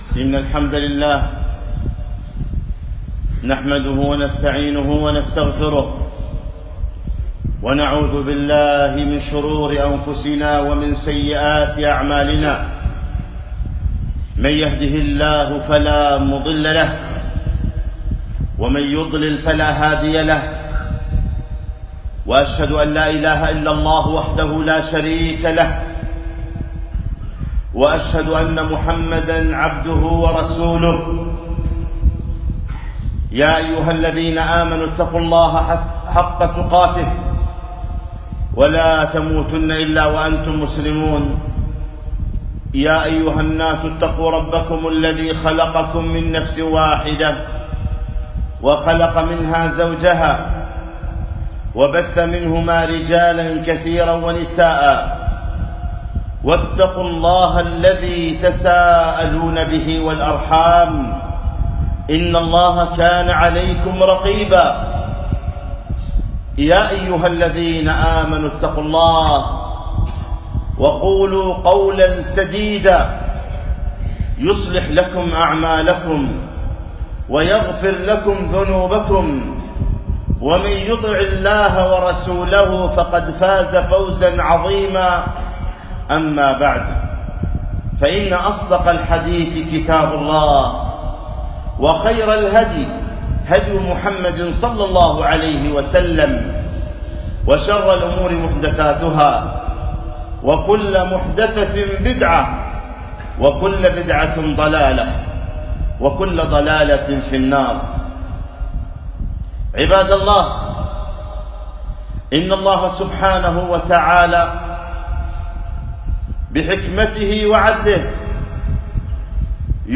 خطبة - الاعتبار في الريح والغبار